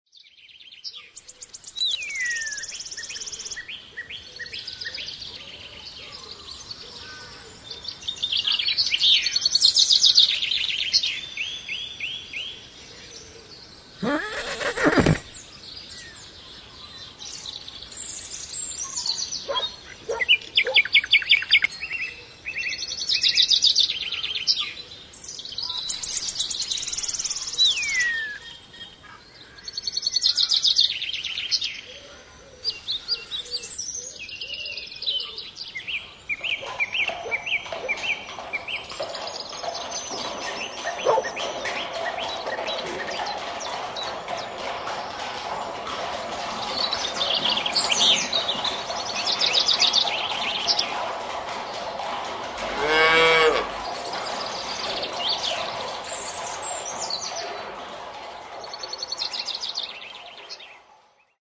Country Stable
Category: Animals/Nature   Right: Personal